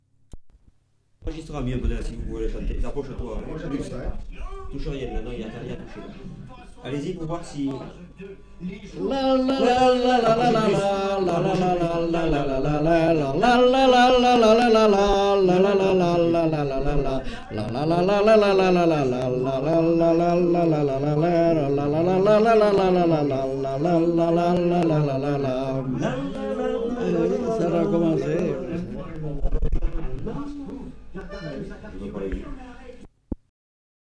Rondeau (fredonné)